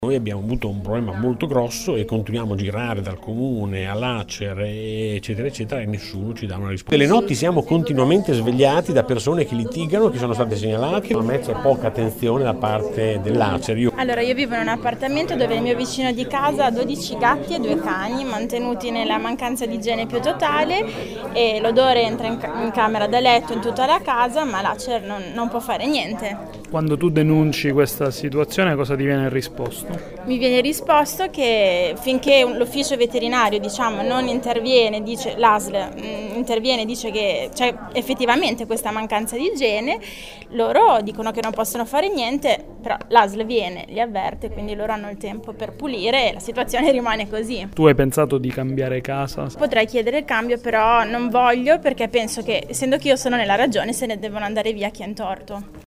Ascolta le testimonianze di due inquilini Erp